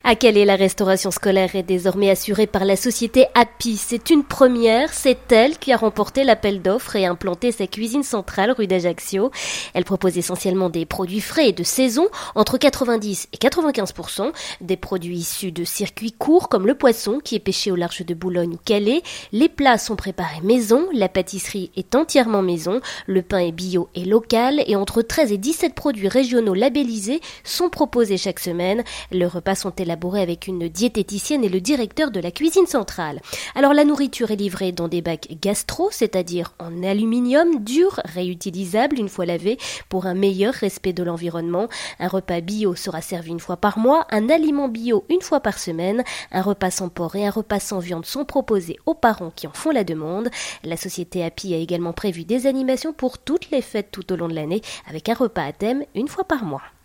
A l’occasion de la rentrée, Natacha Bouchart, la maire de Calais a organisé lundi matin une conférence de presse au sein de l’école primaire Esplanade afin de faire le point sur la restauration scolaire.